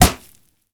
punch_head_weapon_bat_impact_01.wav